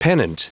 Prononciation du mot pennant en anglais (fichier audio)
Prononciation du mot : pennant